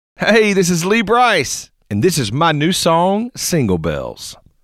Liners